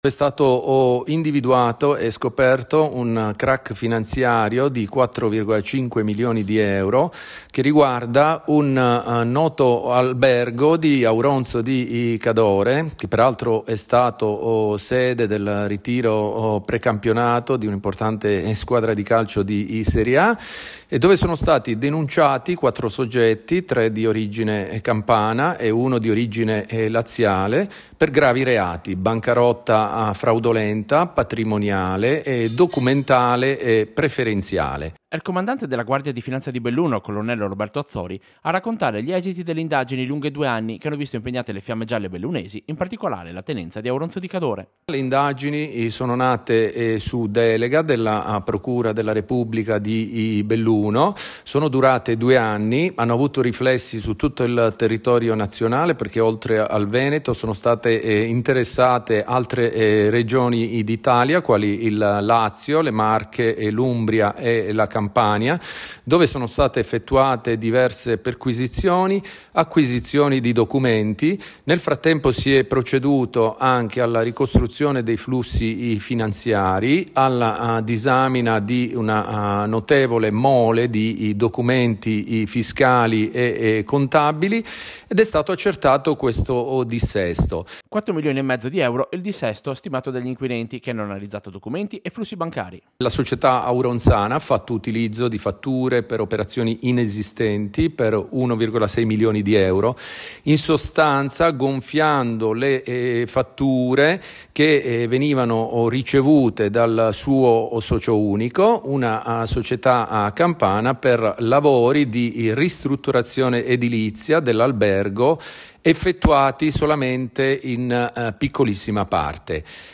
Servizio-Crac-Auronzo-GdF-1.mp3